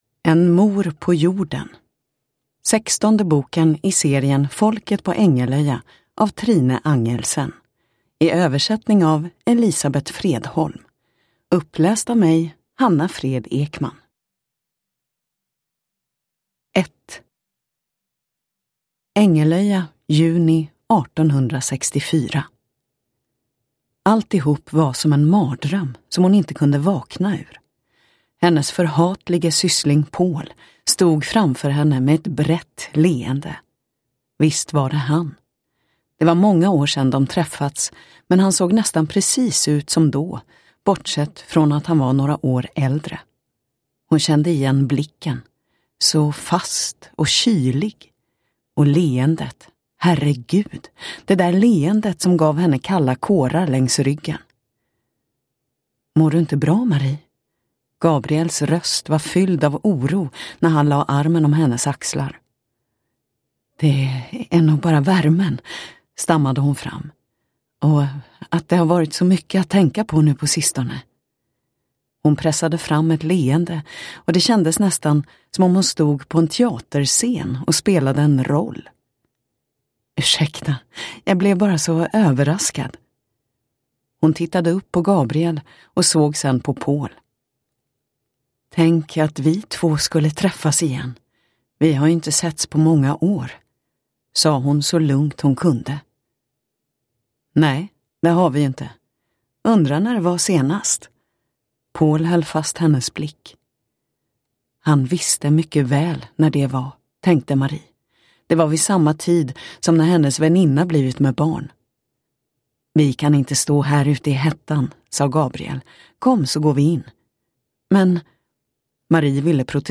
En mor på jorden – Ljudbok – Laddas ner